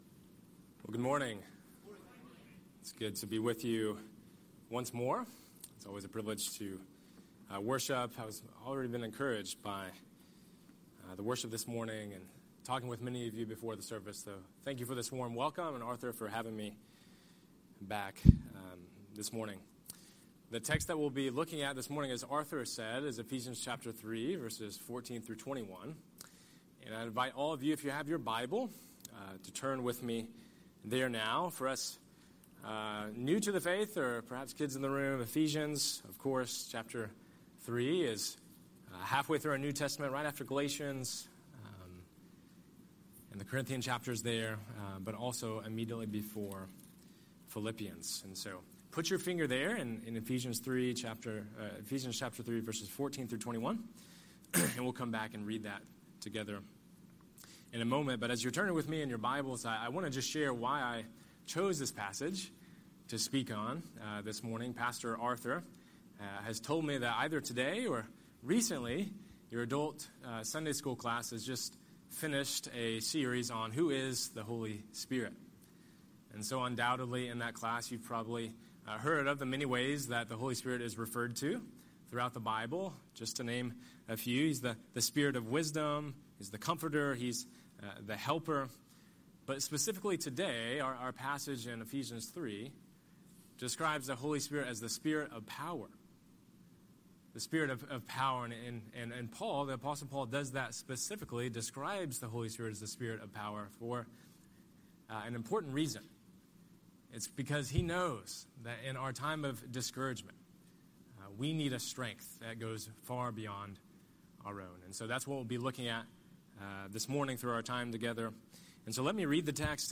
Scripture: Ephesians 3:14–21 Series: Sunday Sermon